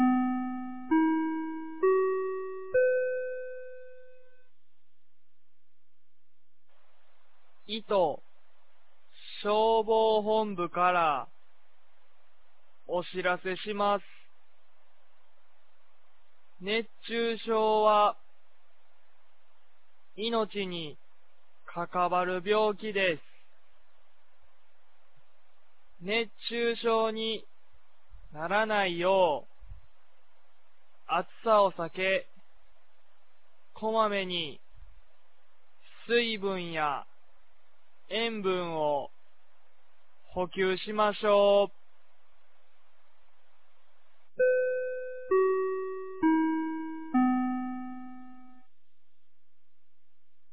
2024年06月24日 10時01分に、九度山町より全地区へ放送がありました。
放送音声